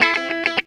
GTR 70.wav